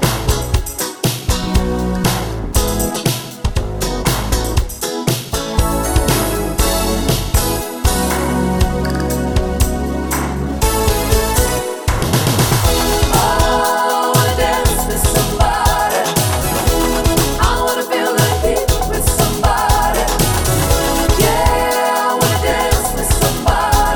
One Semitone Down Pop (1980s) 4:54 Buy £1.50